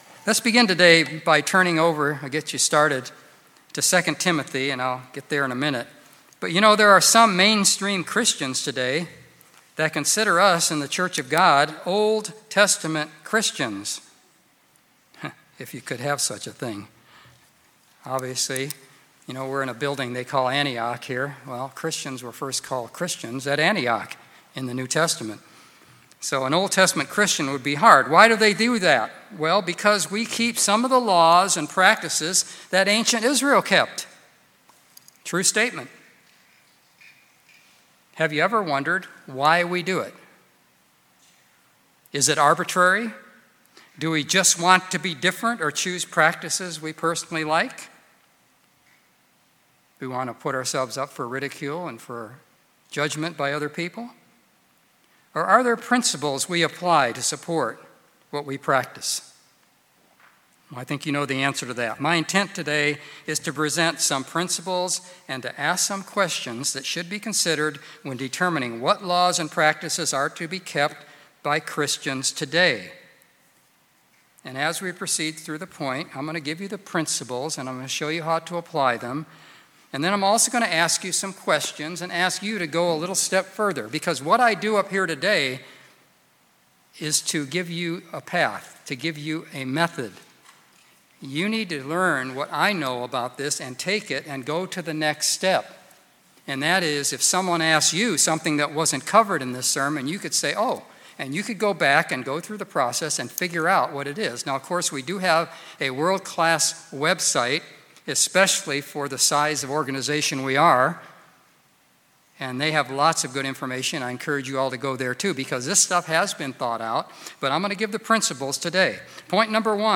Sermons
Given in Greensboro, NC Jacksonville, NC Raleigh, NC